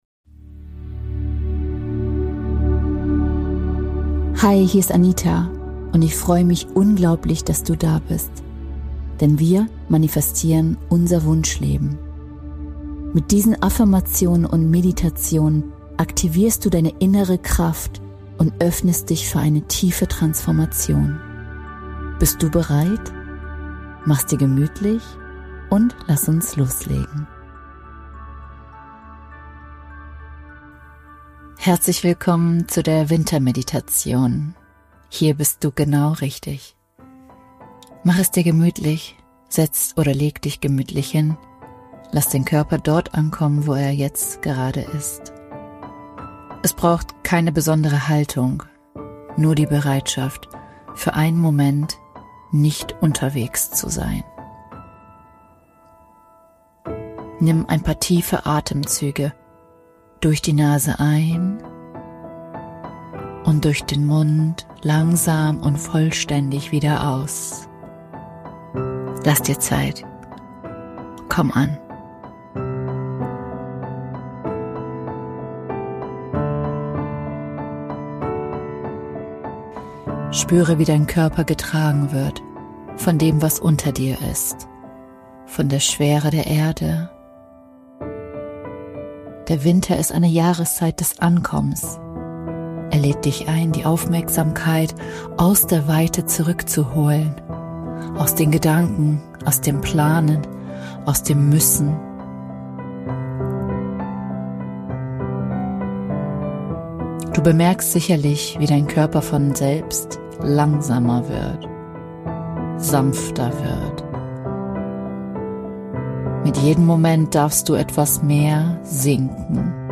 In dieser geführten Wintermeditation wirst du eingeladen, dich dem Zauber des Schnees hinzugeben – der Stille, der Klarheit und dem Rückzug dieser besonderen Jahreszeit.